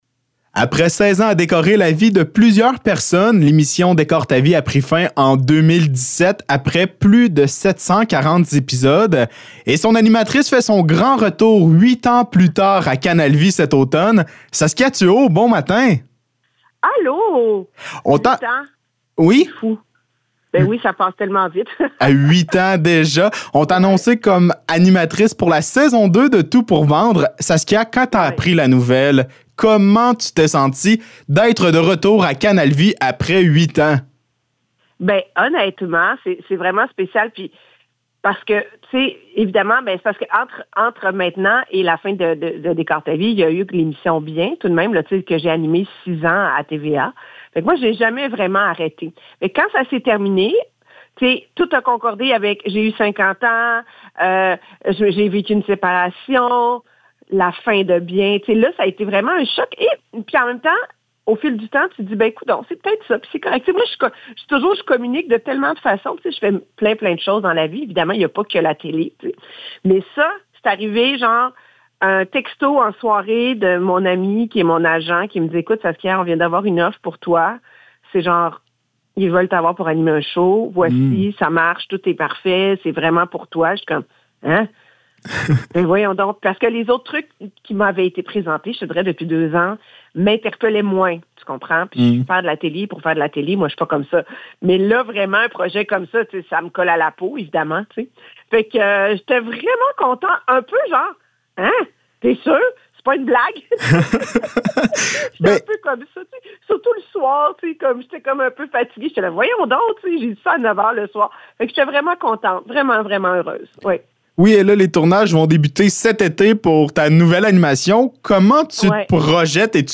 Entrevue avec Saskia Thuot
ENTREVUE-SASKIA-THUOT.mp3